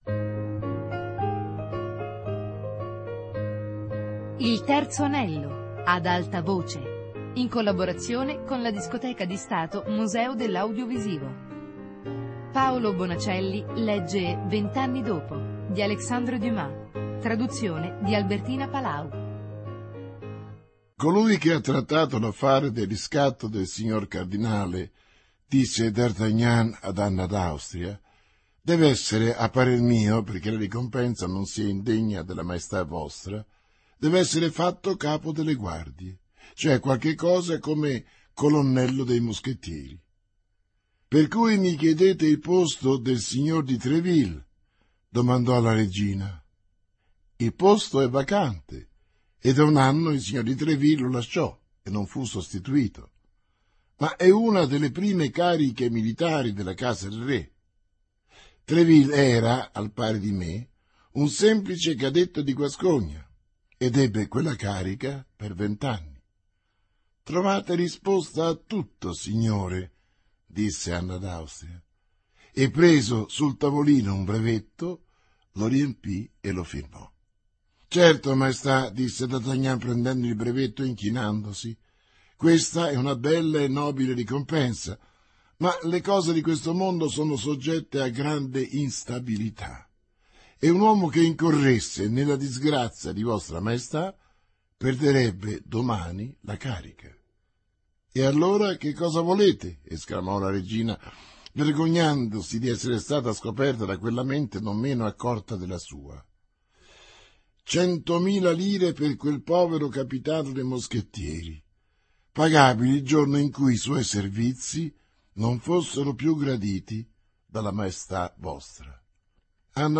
Vent'anni dopo - Lettura XLII